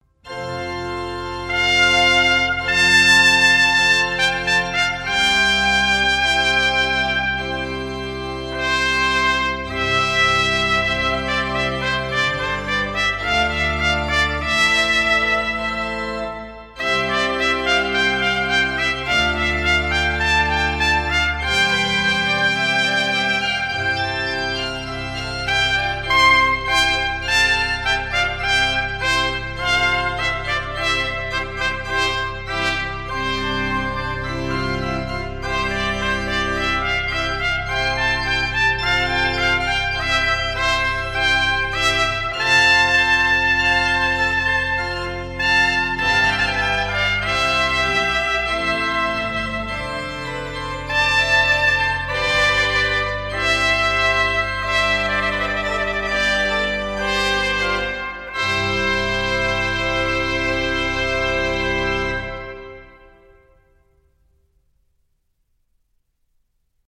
015 - Sonata prima for Trumpet and Continuo _ V. (Adagio).mp3